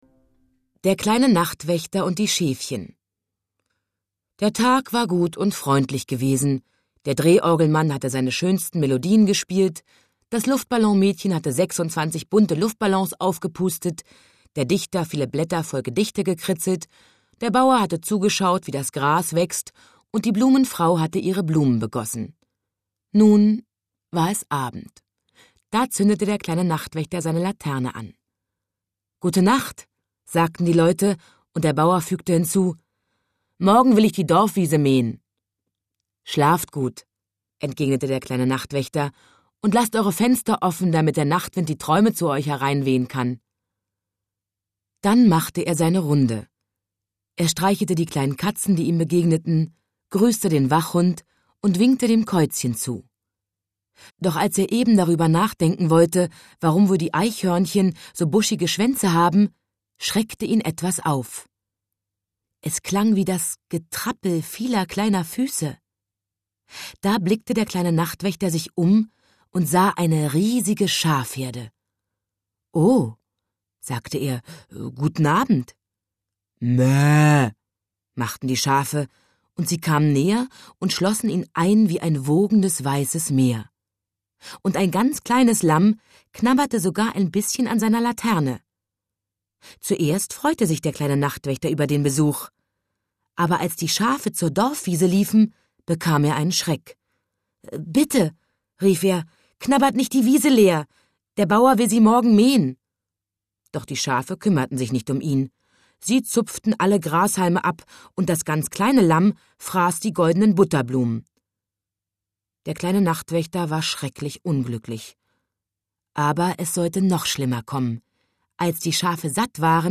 Nach dem großen Erfolg des ersten Teils folgen nun zwanzig weitere Abenteuer rund um den kleinen Zauberer, den kleinen Zoowärter und viele weitere wunderbare Figuren. Christiane Paul liest die seit Jahrzehnten beliebten Gutenachtgeschichten.